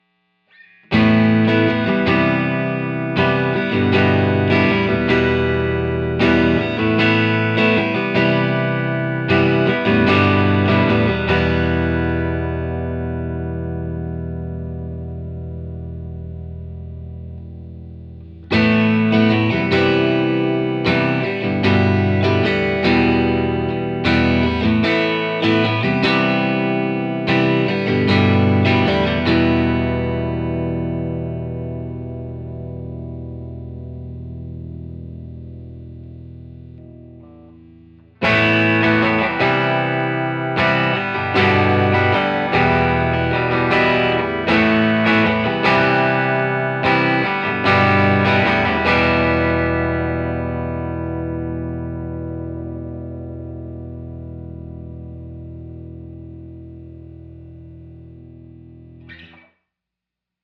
1959 Tele Set Fender Super Reverb / AlNiCo Speakers - Open Chords
59_Open_Chords.wav